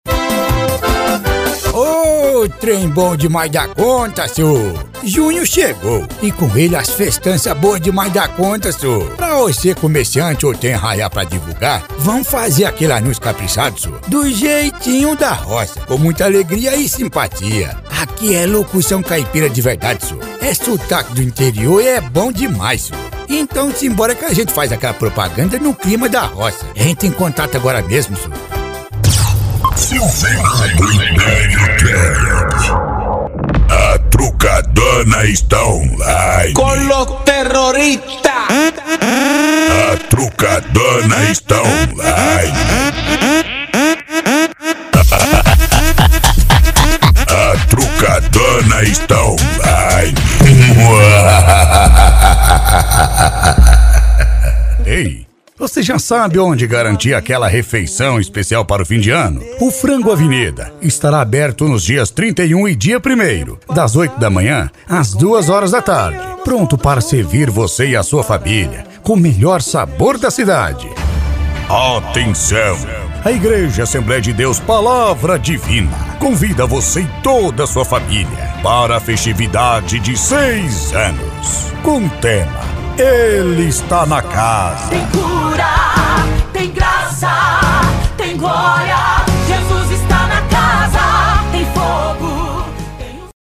voz do papai Noel :